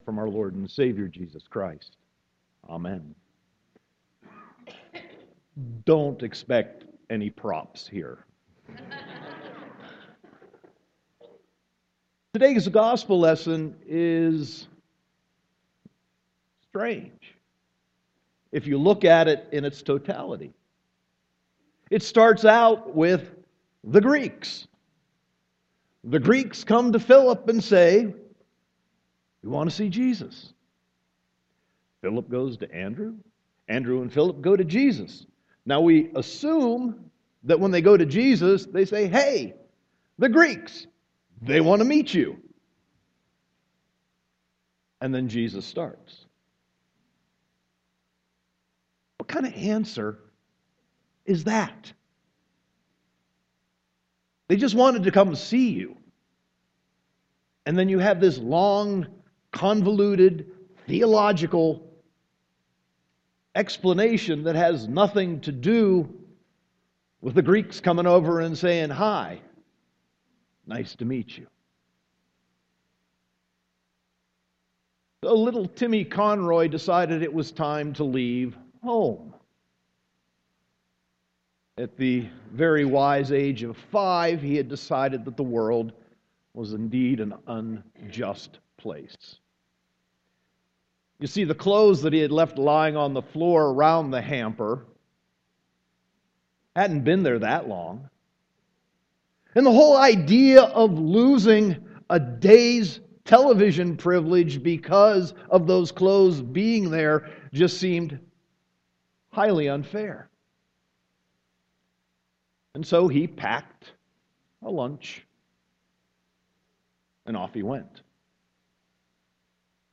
Sermon 3.22.2015